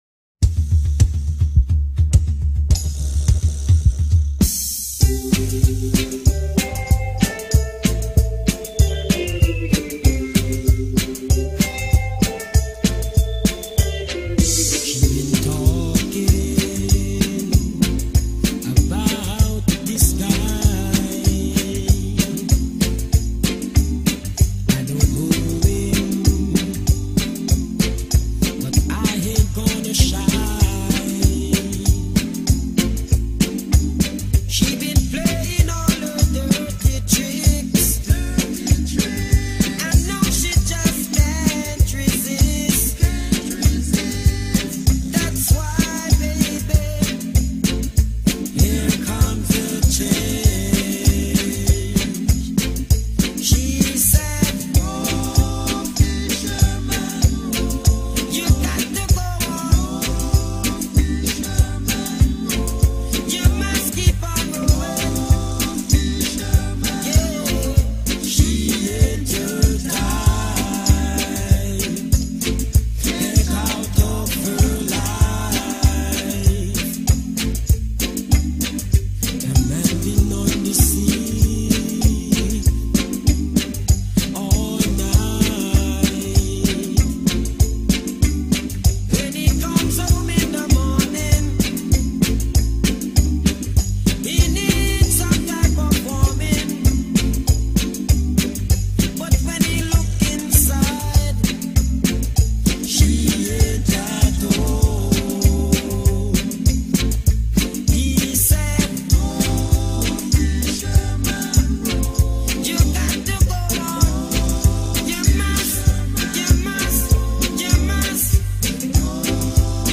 Reggae group
Roots Reggae